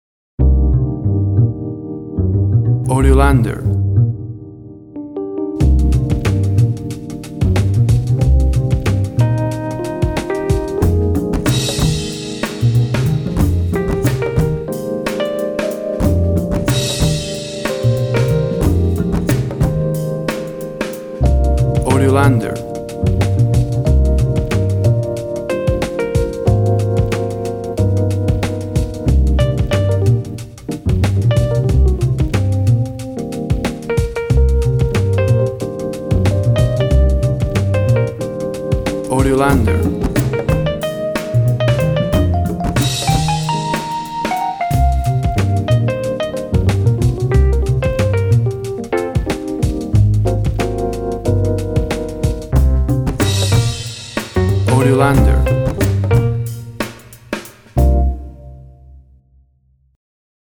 Sounds of urban summer landscapes.
WAV Sample Rate 16-Bit Stereo, 44.1 kHz
Tempo (BPM) 93